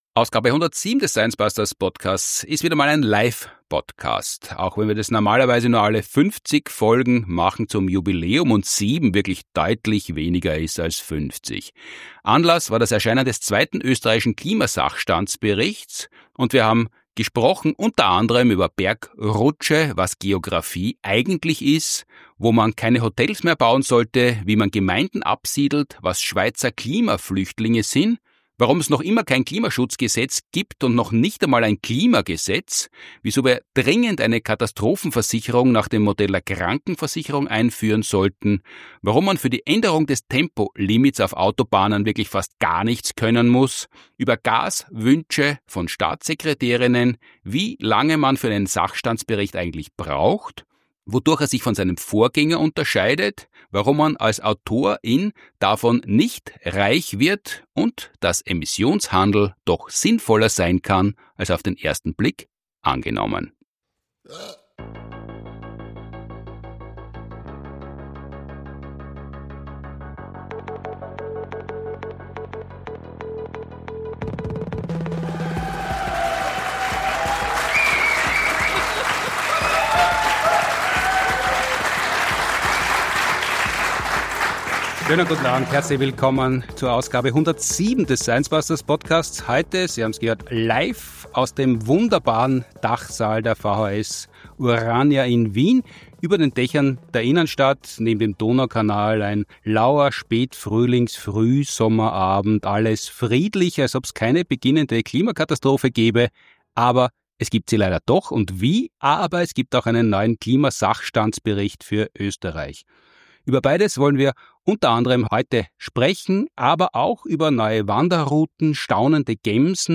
Nachhaltige Wissenschaftskommunikation, wenn man so will, lehrreich, unterhaltsam und durchgehend in Stereo. In jeder Folge wird eine aktuelle Frage der Hörerschaft beantwortet, oder zwei. Und ein Thema umfassend beackert.